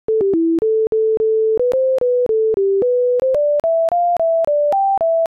Hair thickness is proportional to the number of different patterns in a tune, and these tunes have, relatively speaking, a small number of different patterns, largely because they lack tied notes and large intervals, features common to many folk tunes.